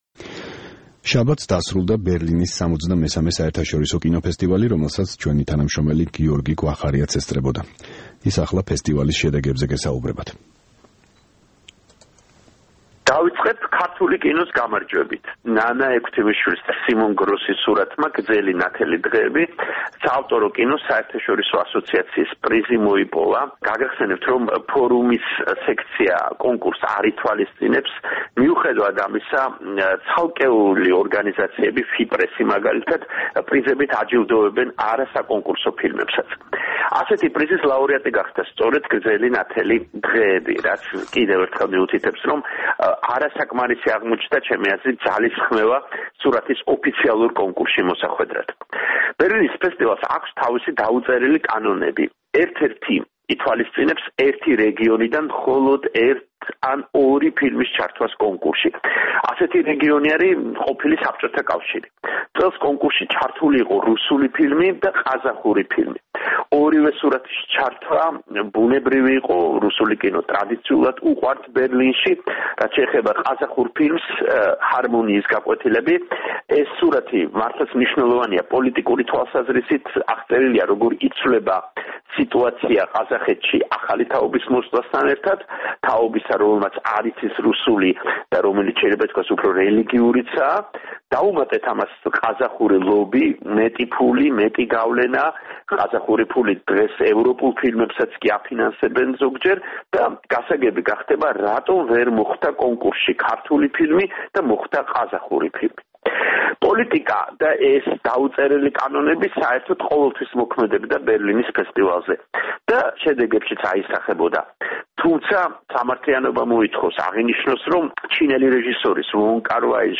რეპორტაჟი ბერლინალეს შესახებ